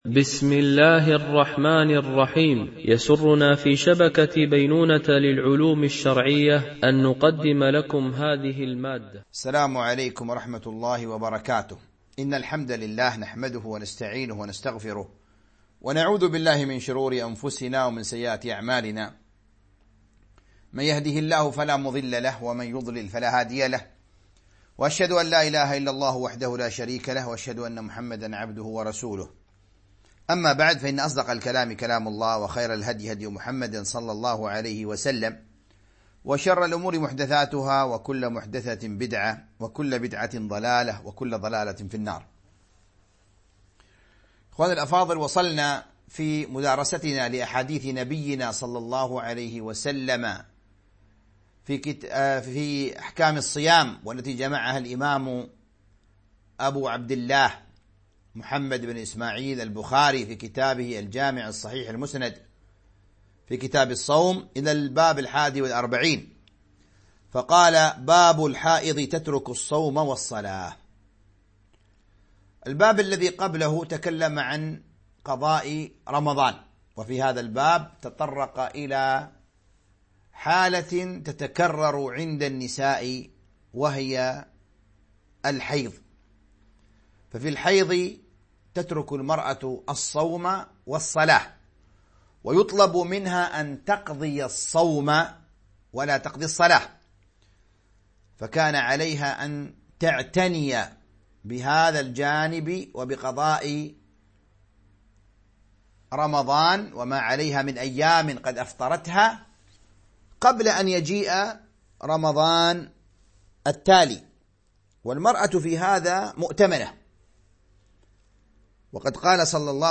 التعليق على كتاب الصيام من صحيح البخاري ـ الدرس 12
التنسيق: MP3 Mono 22kHz 32Kbps (CBR)